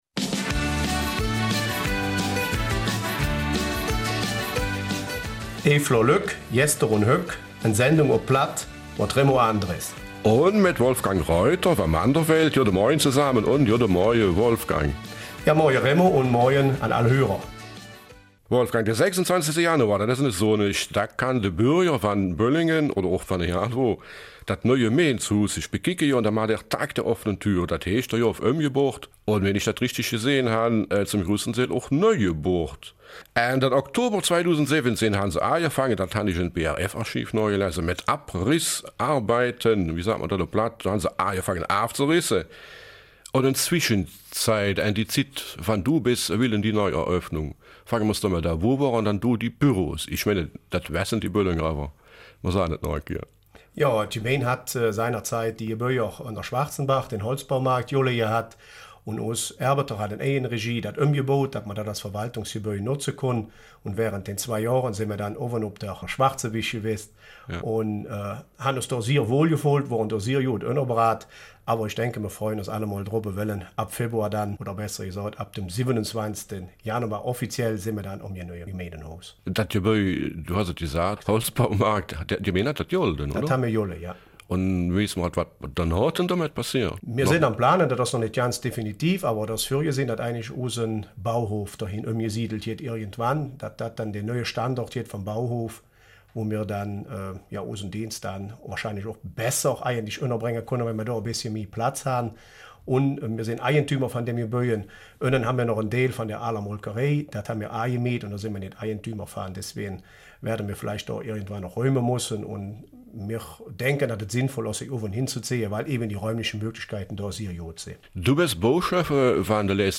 Eifeler Mundart - 12.